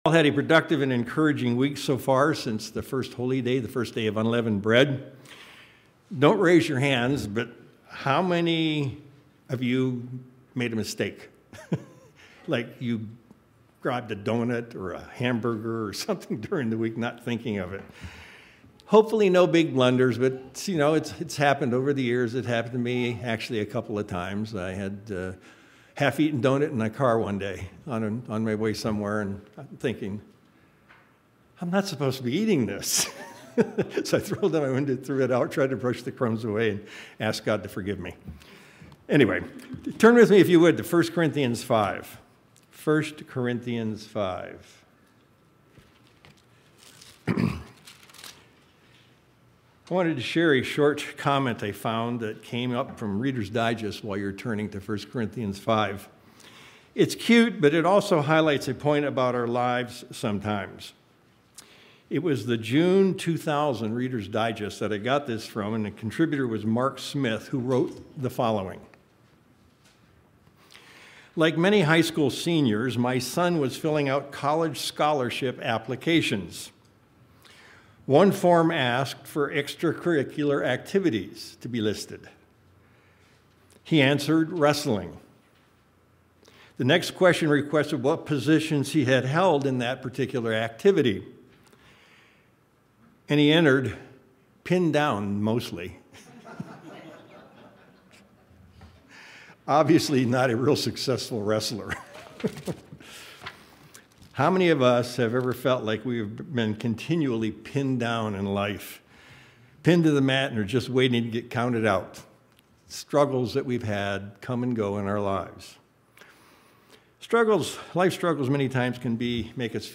During the Days of Unleavened Bread we can get distracted, but we need to understand that we can get on track and understand that everything we do God can make work out for the good. That's what we will adddress in the sermon today.